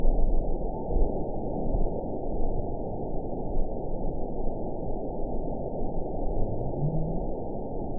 event 917117 date 03/21/23 time 01:04:25 GMT (2 years, 1 month ago) score 9.44 location TSS-AB04 detected by nrw target species NRW annotations +NRW Spectrogram: Frequency (kHz) vs. Time (s) audio not available .wav